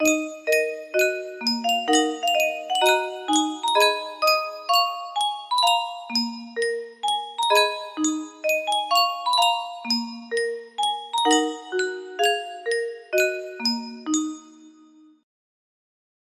Yunsheng Music Box - Unknown Tune 1045 music box melody
Full range 60